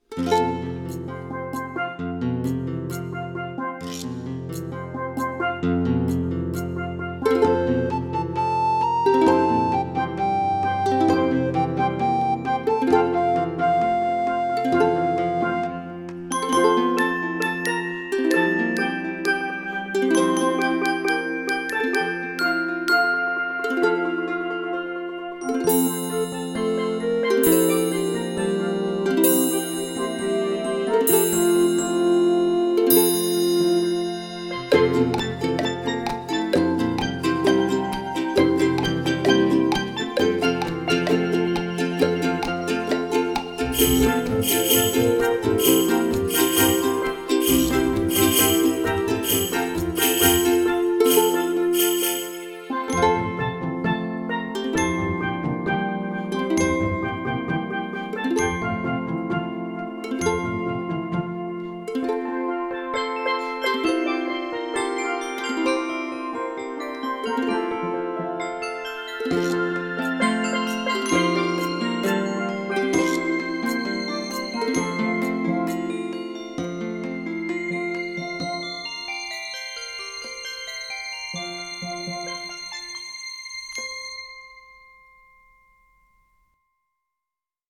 This popular lullaby is given a Caribbean feel in this arrangement, with the sounds of steel pans and hand drums.
hush_little_baby.mp3